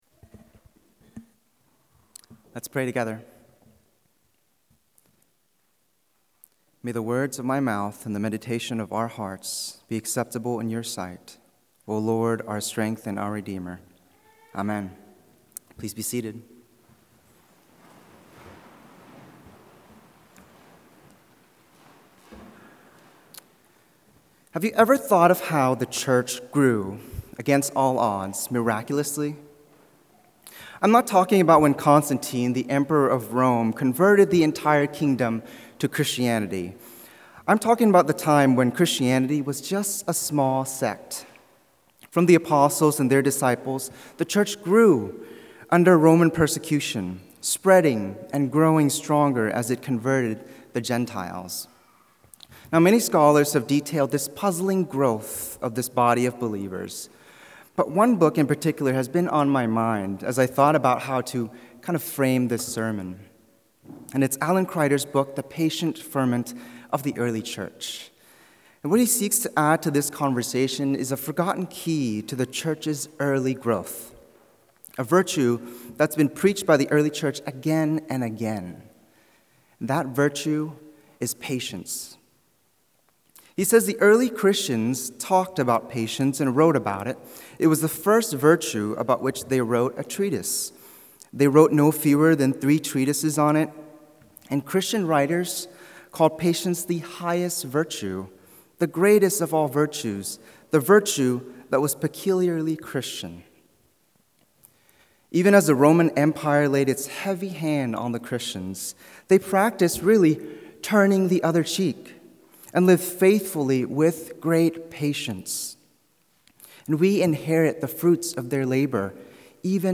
Download Download Pentecost 2025 Current Sermon Patience with God